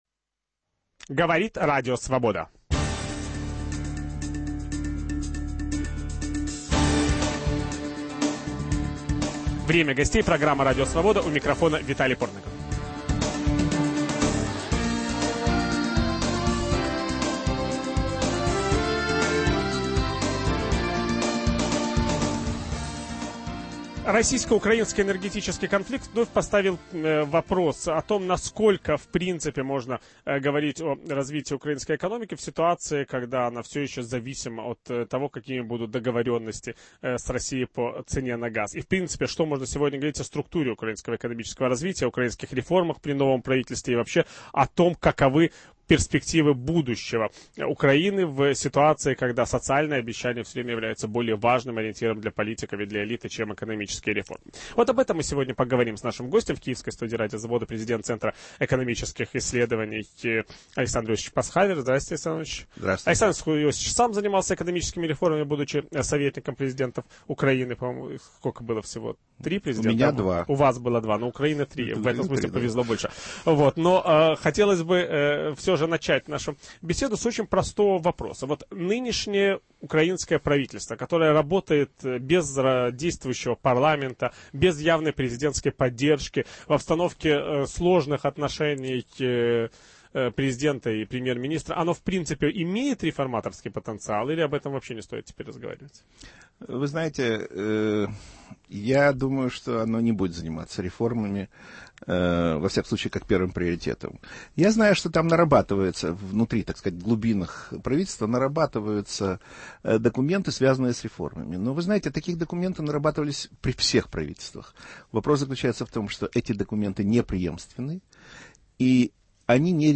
О украинской экономической ситуации и перспективах российско-украинского сотрудничества с ведущим программы Виталием Портниковым беседует президент Центра экономического развития Александр Пасхавер